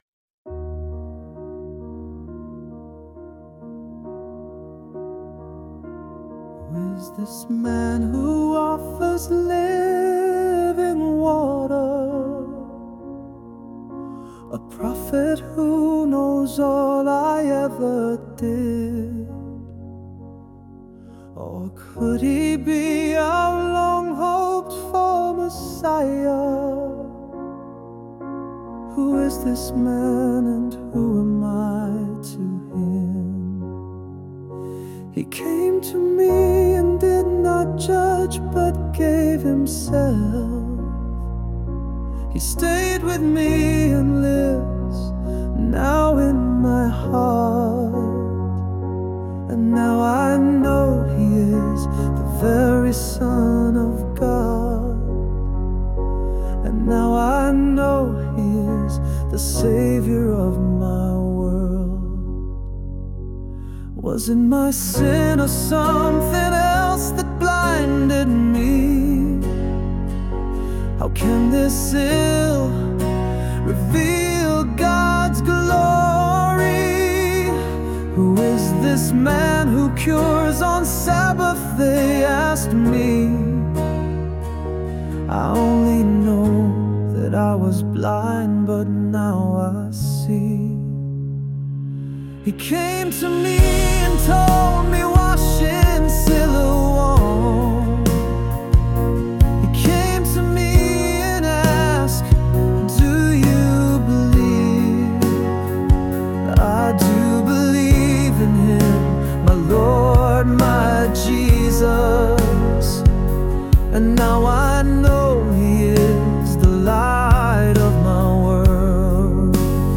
Rock Ballad